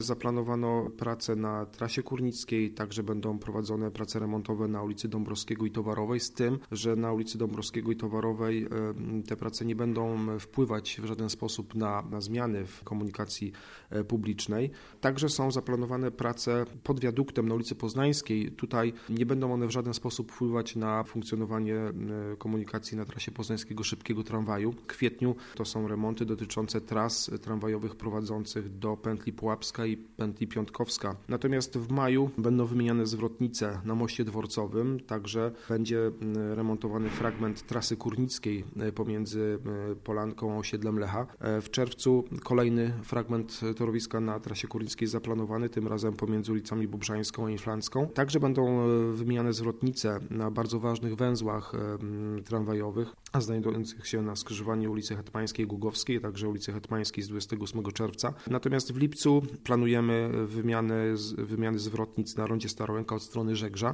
Naszym gościem był Miejski Inżynier Ruchu, Łukasz Dondajewski, który zatwierdza każdy remont w Poznaniu i odpowiada za organizację ruchu w mieście.